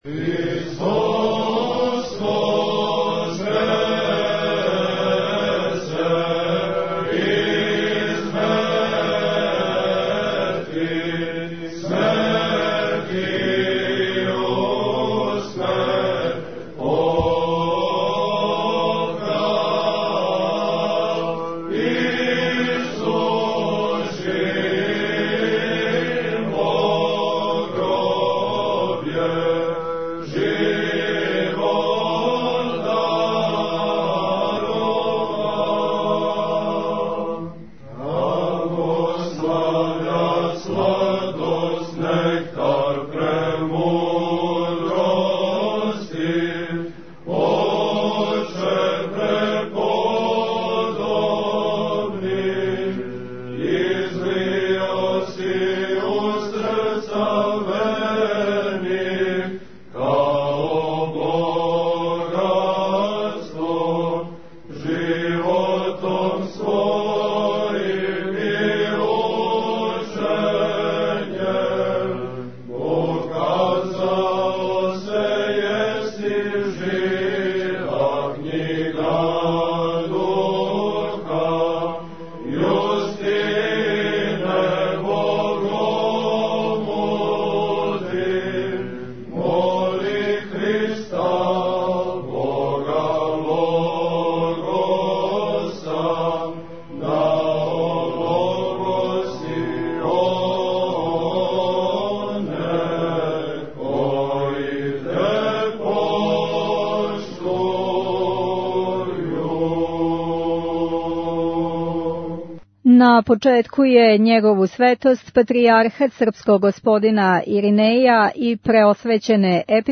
У програму је учествовао Хор студената Православног богословског факултета Универзитета у Београду, а затим су студенти читали одломке из докумената који сведоче о обнови Патријаршије из 1920. године, као и беседу Патријарха Гаврила Дожића са устоличења.
Звучни запис свечаности